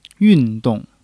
yun4--dong4.mp3